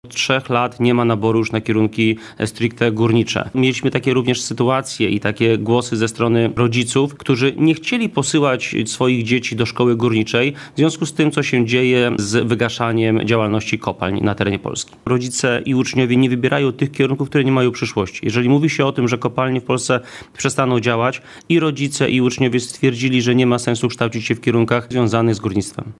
Starosta łęczyński Łukasz Reszka mówi, że decyzja o likwidacji wynika z braku zainteresowania kształceniem w zawodach górniczych.